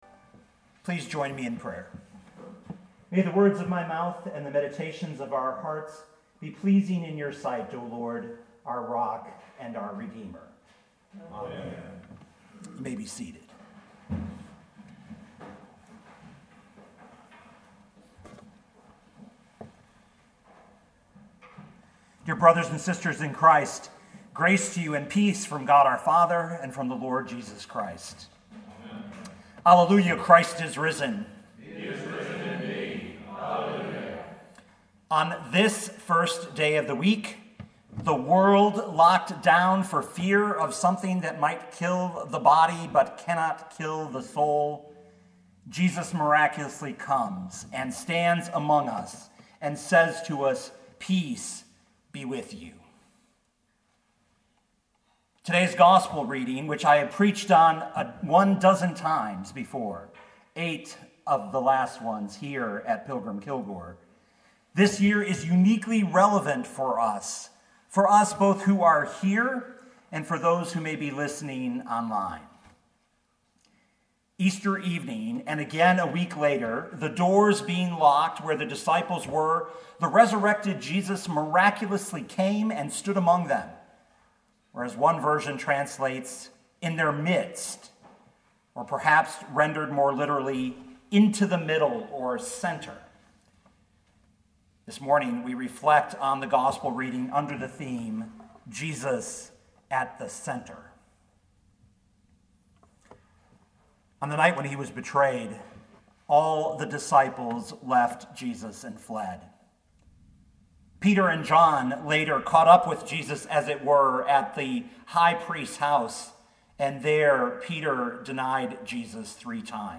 2020 John 20:19-31 Listen to the sermon with the player below, or, download the audio.